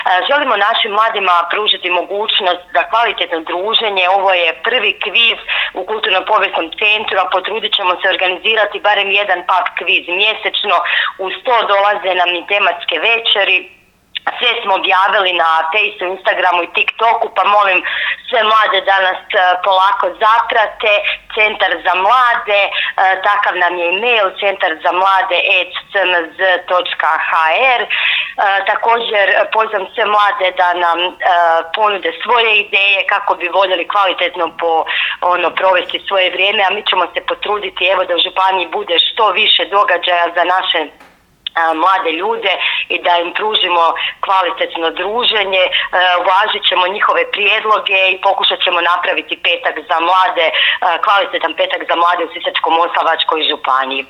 Više doznajemo od Robertine Štajdohar županijske pročelnice Upravnog odjela za obrazovanje, kulturu, šport, mlade i civilno društvo